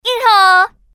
關閉叫號功能 女台語音 >>試聽 男台語音 >>試聽 女國語音 >>試聽 男國語音 >>試聽
01_girl.mp3